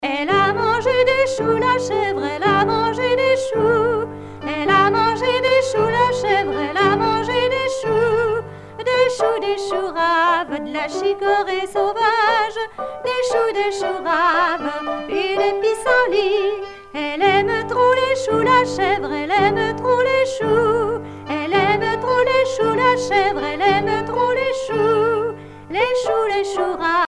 Couplets à danser
danse : ronde
Pièce musicale éditée